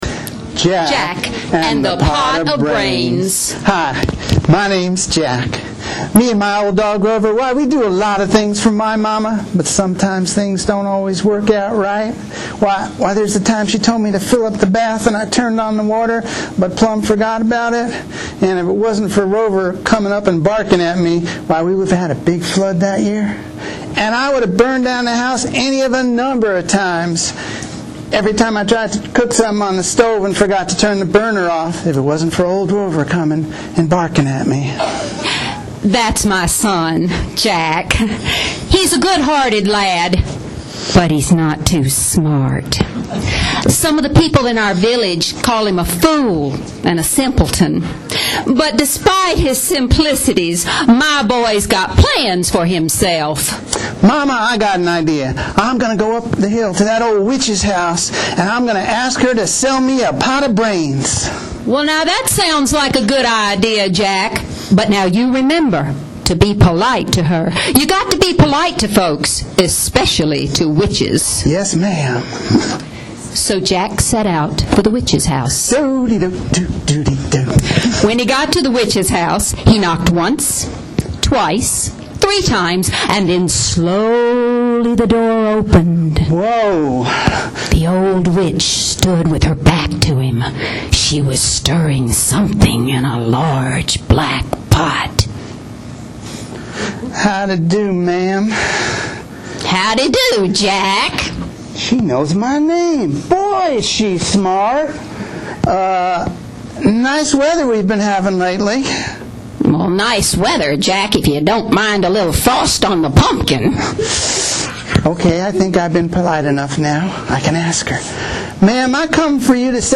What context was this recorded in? Here is an excerpt of our performance on Friday: